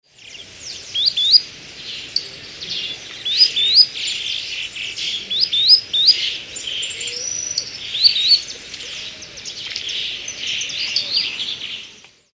Actitis macularius
Nome em Inglês: Spotted Sandpiper
Aprecie o canto do
Maçarico-pintado
macaricopintado.mp3